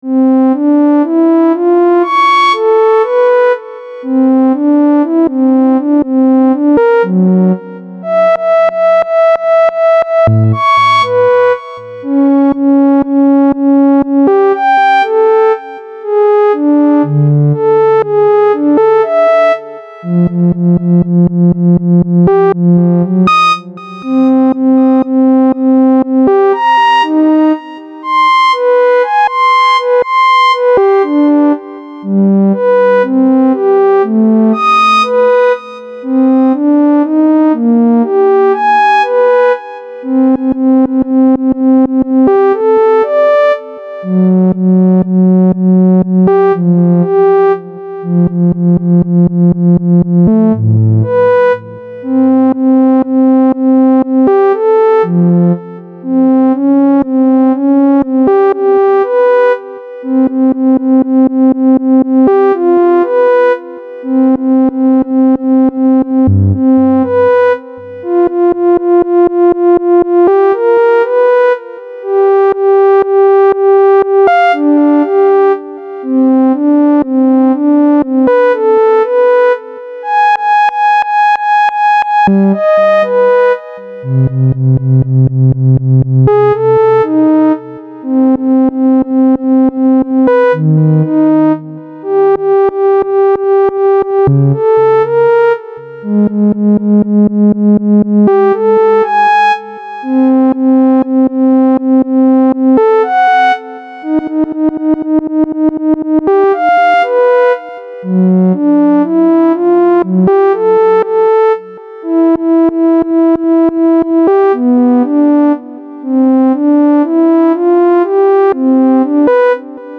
Electrónica experimental
Música electrónica
estridente
melodía
repetitivo
sintetizador